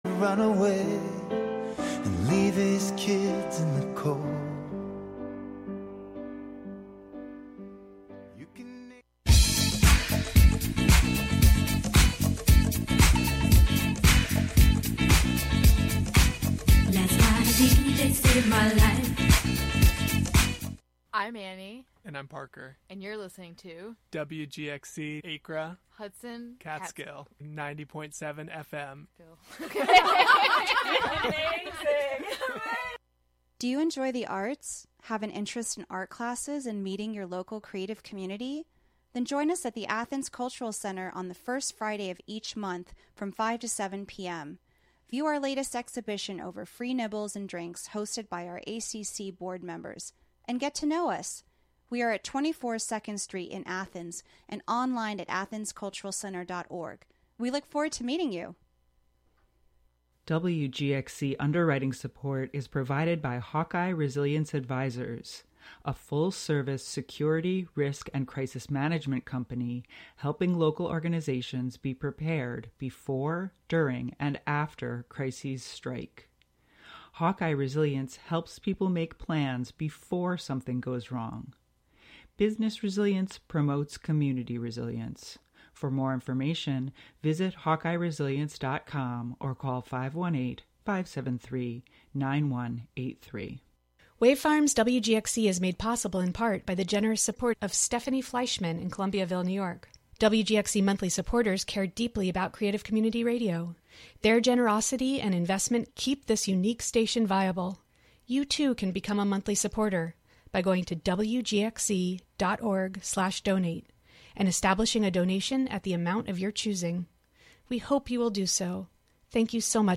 entertainment news and reviews, special features, and the "Forgotten Decades" music mix of lesser-played and/or misremembered songs from the '50s through the '90s.